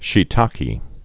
(shē-täkē, shēē-täkĕ)